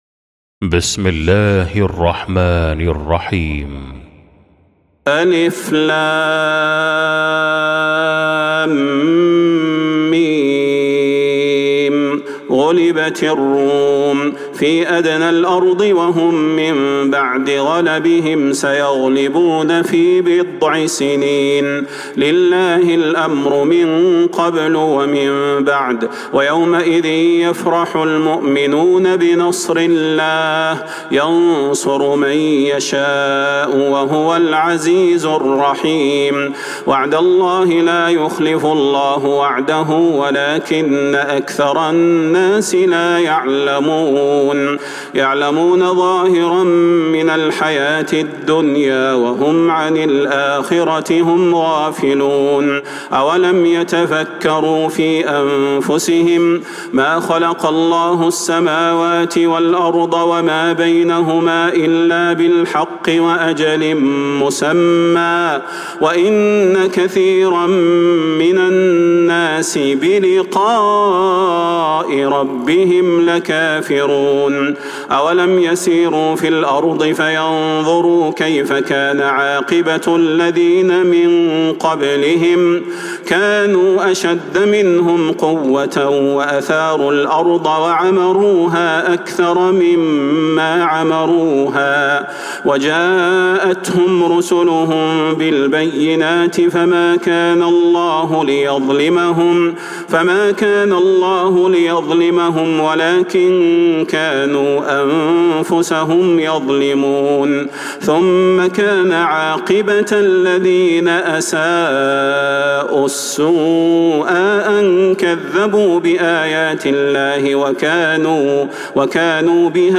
سورة الروم | Surah Ar-Rum > مصحف تراويح الحرم النبوي عام 1446هـ > المصحف - تلاوات الحرمين